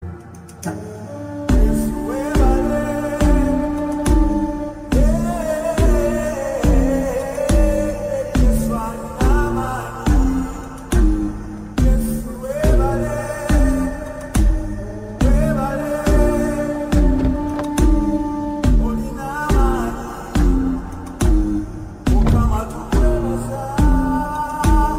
Adventure-Inspired Background Ringtone
is an inspirational background music track